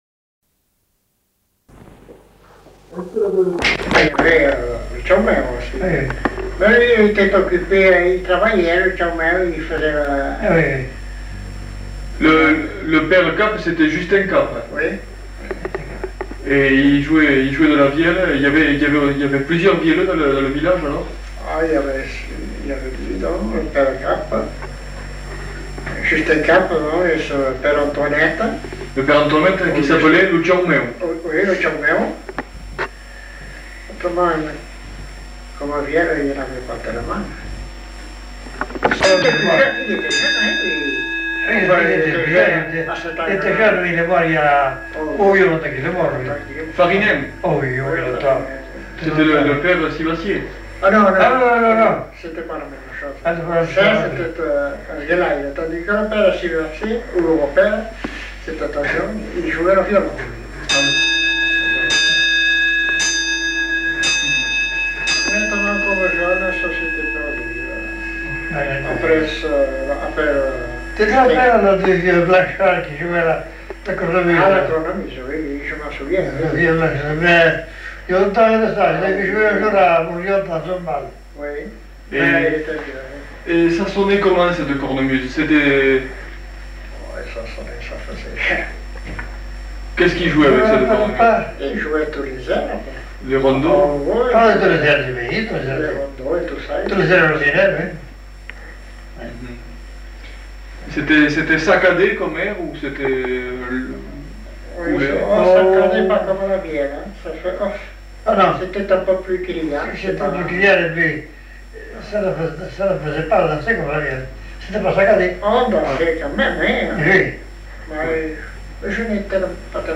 Aire culturelle : Petites-Landes
Genre : témoignage thématique